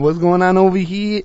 l_whatsgonnaonhere.wav